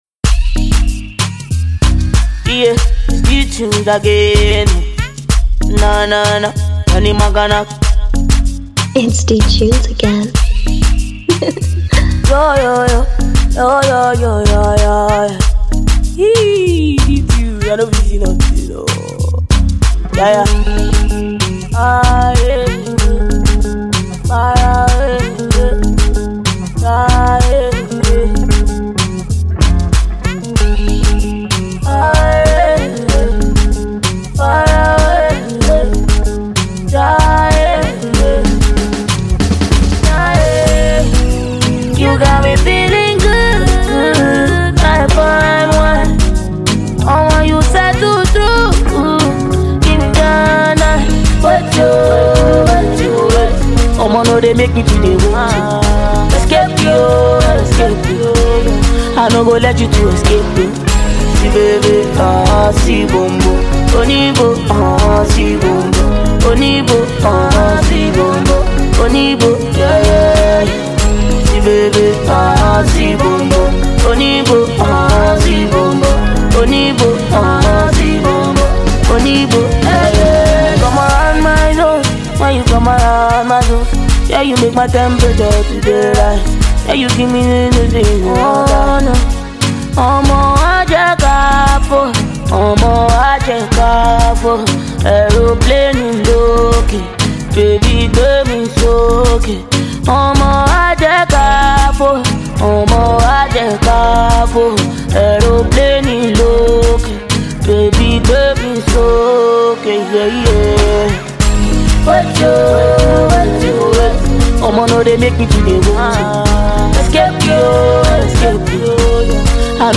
hot banger